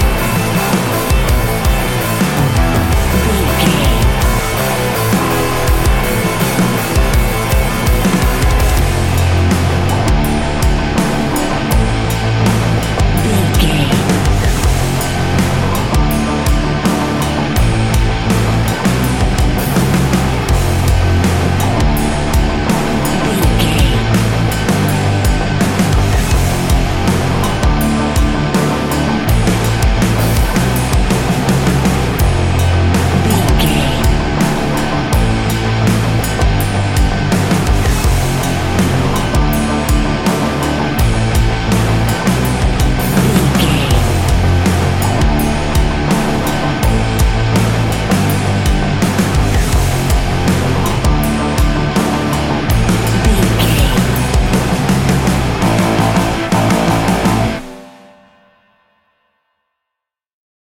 Ionian/Major
D♭
hard rock
guitars